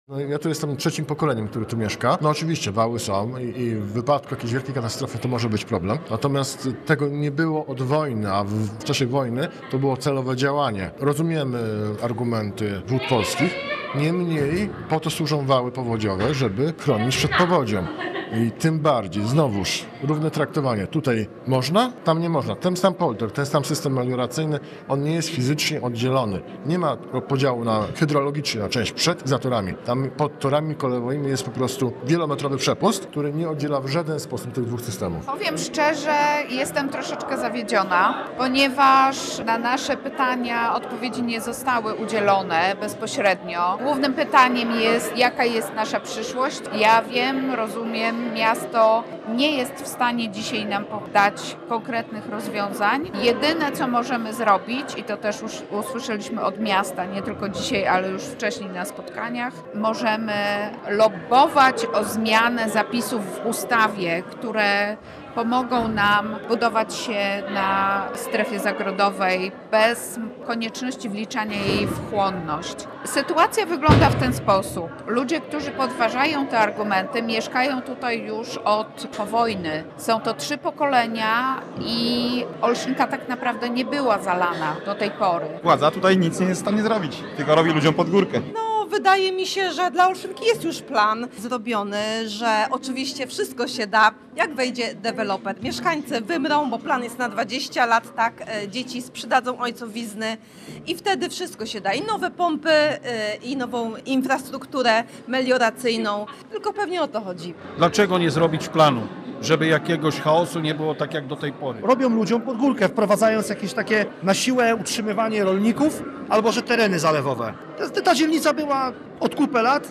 W trakcie spotkania z urzędnikami i prezydent Aleksandrą Dulkiewicz wielokrotnie padał postulat odrolnienia terenów w dokumentach planistycznych.